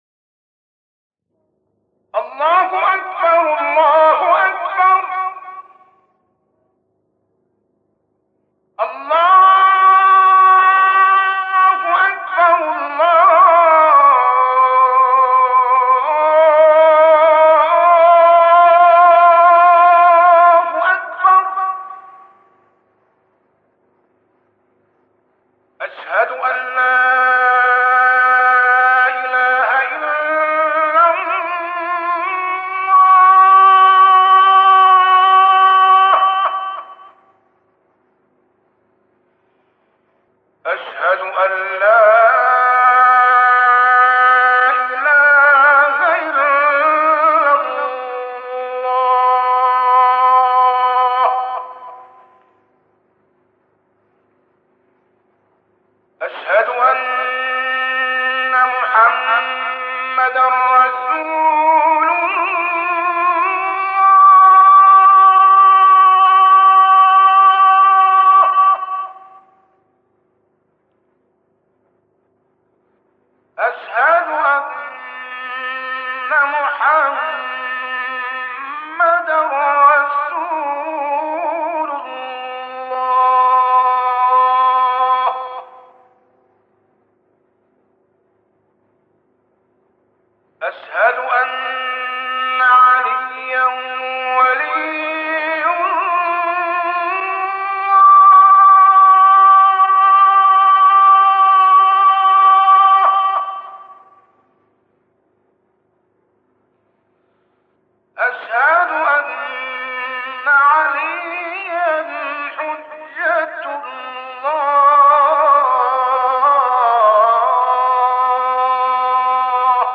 گلبانگ اذان با نوای قاریان مختلف